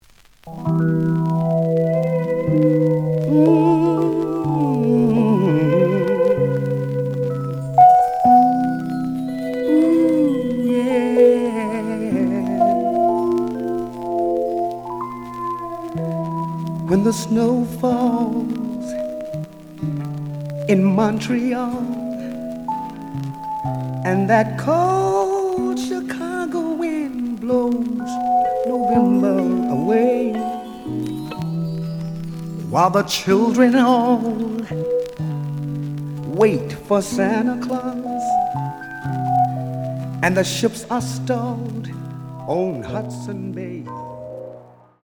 The audio sample is recorded from the actual item.
●Genre: Soul, 80's / 90's Soul
Looks good, but slight noise on both sides.)